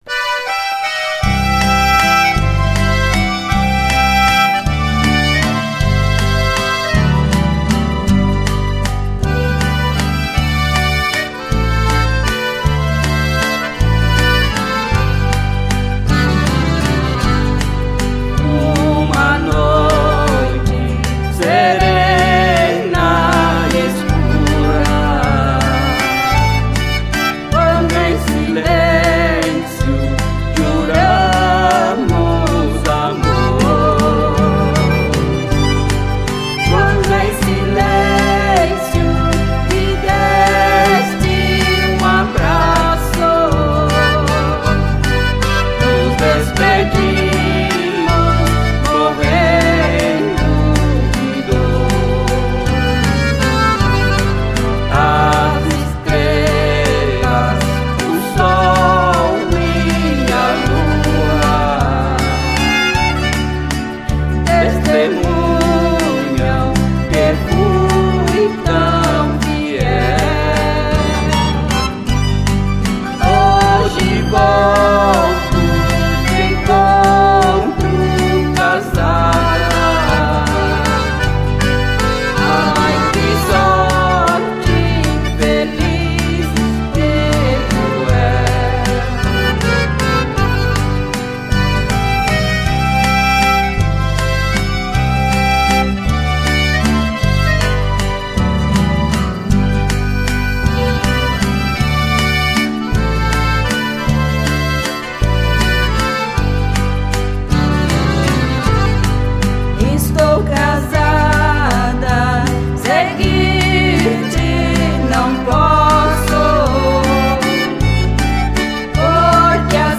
Canção Rancheira